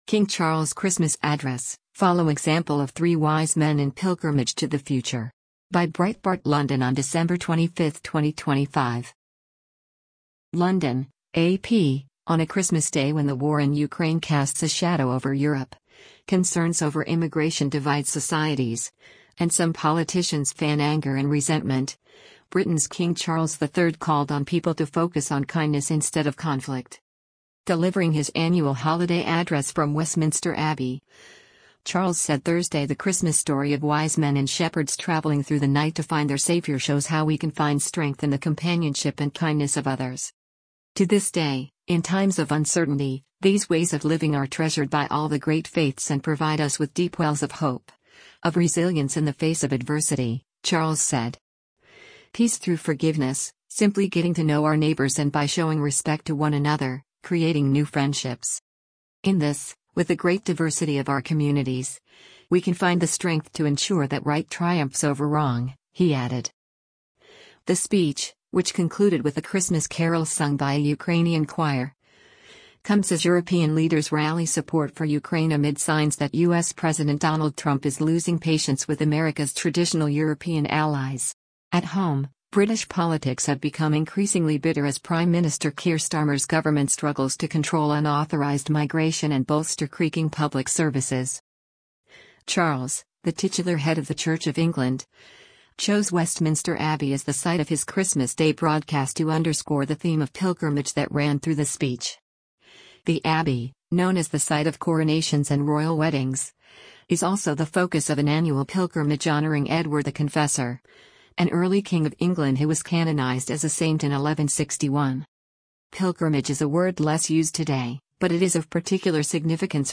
Delivering his annual holiday address from Westminster Abbey, Charles said Thursday the Christmas story of wise men and shepherds traveling through the night to find their savior shows how we can find strength in the “companionship and kindness of others.”
The speech, which concluded with a Christmas carol sung by a Ukrainian choir, comes as European leaders rally support for Ukraine amid signs that U.S. President Donald Trump is losing patience with America’s traditional European allies.
The pre-recorded speech is broadcast at 3 p.m. London time, when many families are enjoying their traditional Christmas lunch.